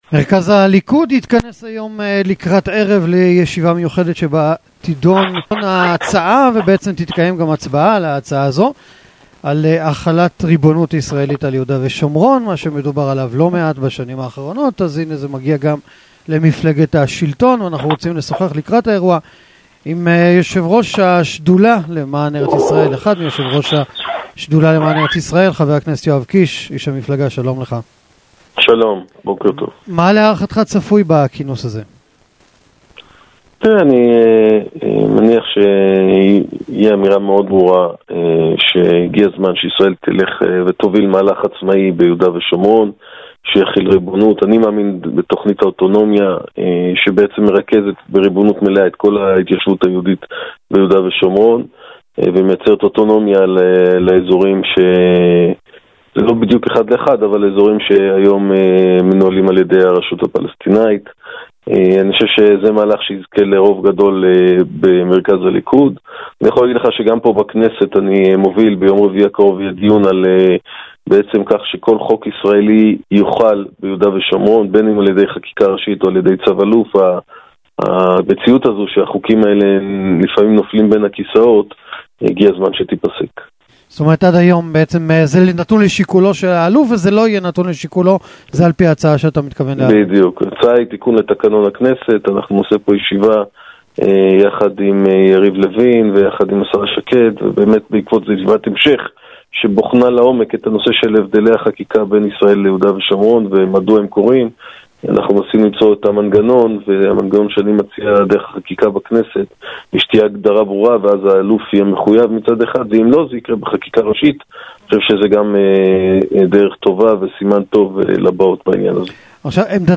Полностью интервью с Йоавом Кишем можно прослушать, перейдя по этой ссылке .